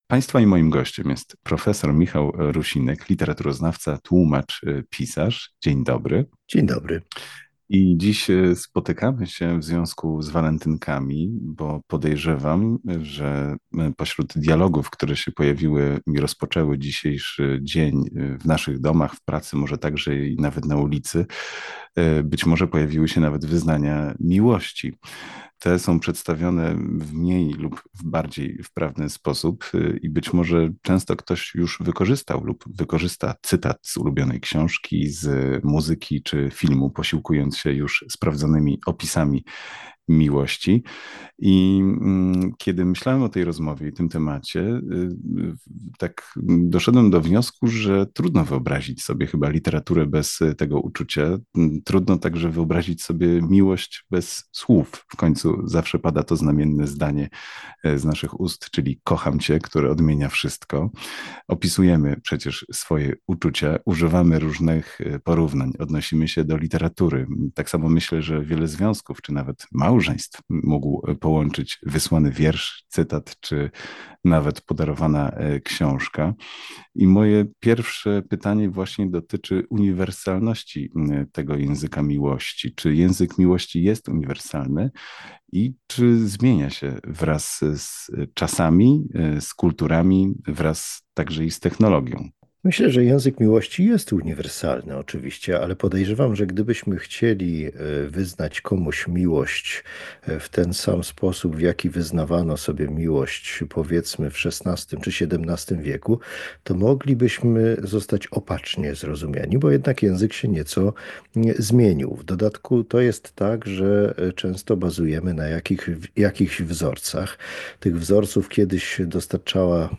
O tym, jak zmieniał się język miłości na przestrzeni dziejów, jakie błędy popełniamy w opisywaniu uczuć i czy emoji mogą być nowoczesną formą poezji miłosnej, rozmawialiśmy z prof. Michałem Rusinkiem – literaturoznawcą, tłumaczem i pisarzem.